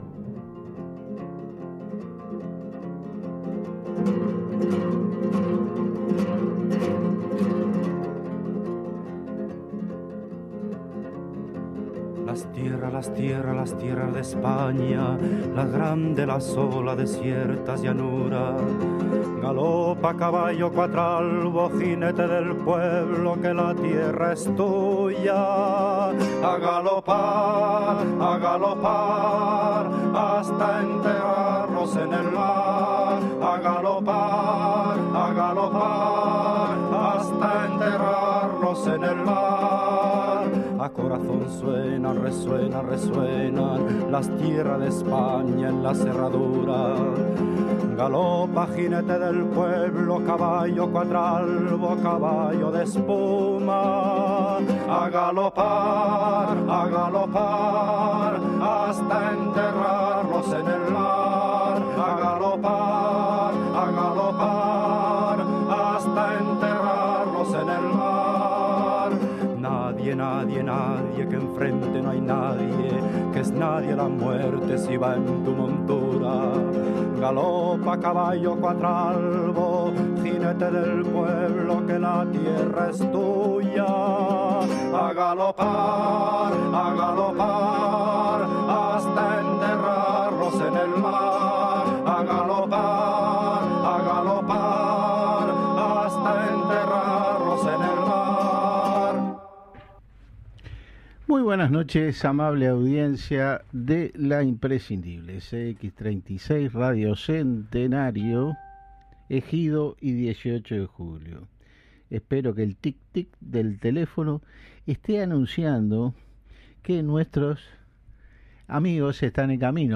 Entrevista en programa Marxianos de CX36